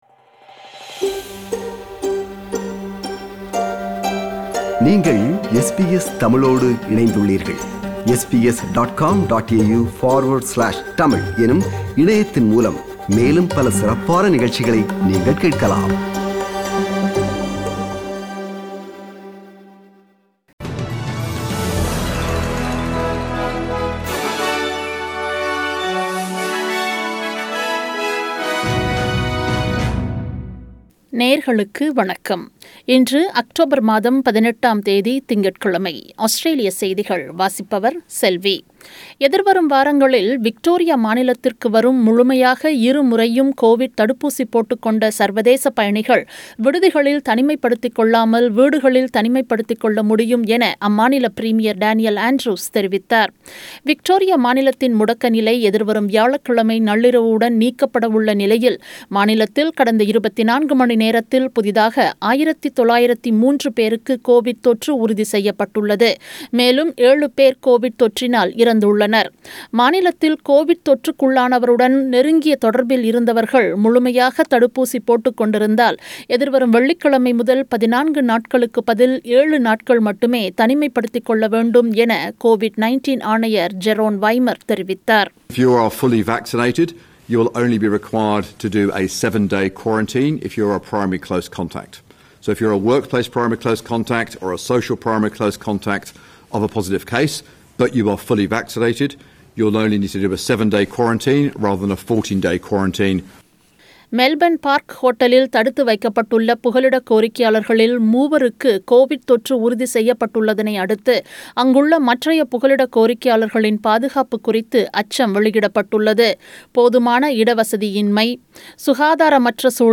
Australian News